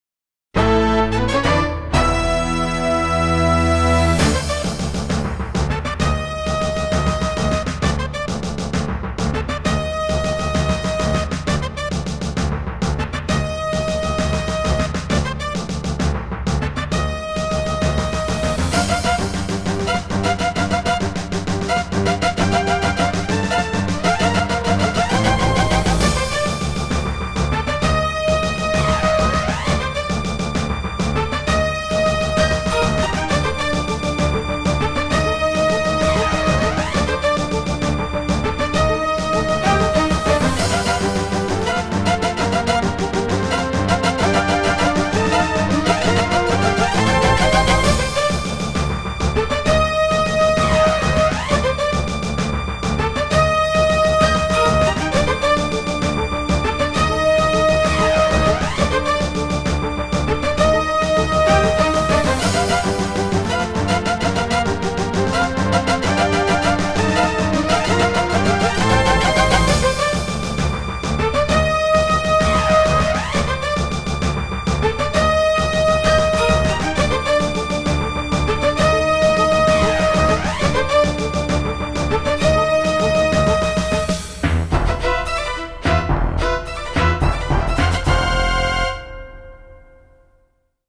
as recorded from the original Roland MT-32 score!